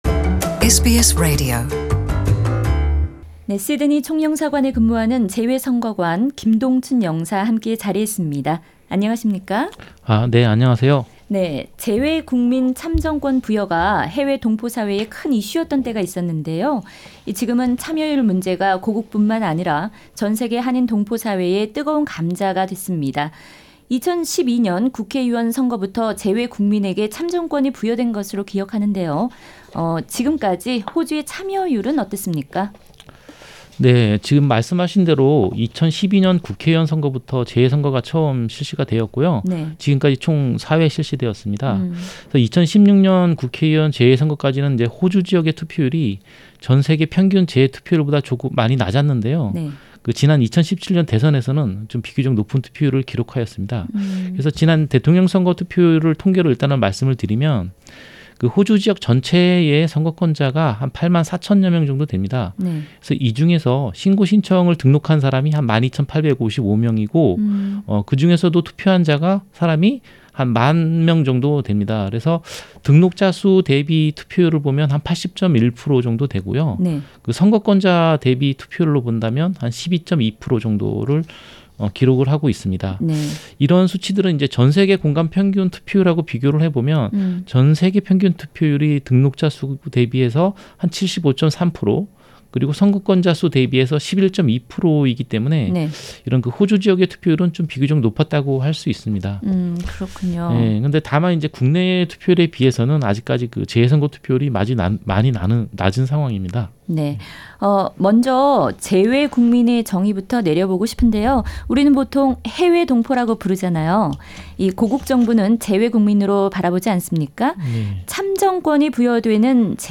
일문일답